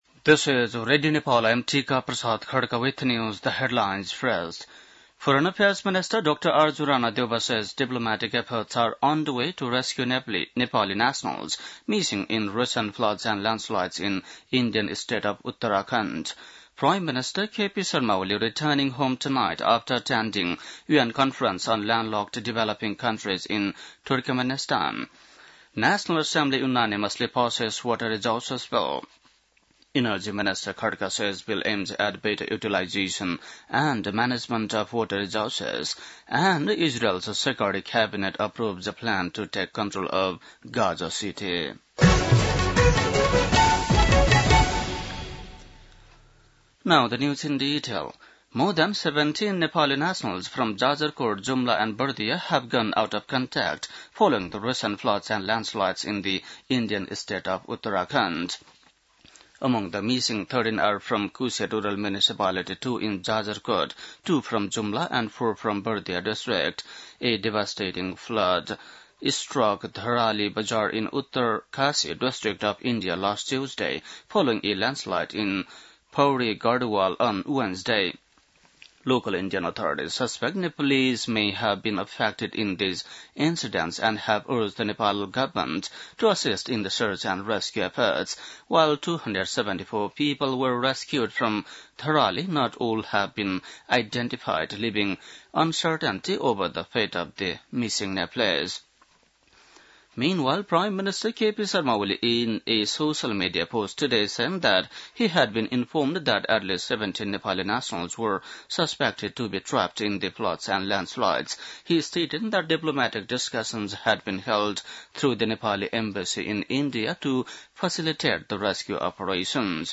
बेलुकी ८ बजेको अङ्ग्रेजी समाचार : २३ साउन , २०८२
8-pm-english-news-4-23.mp3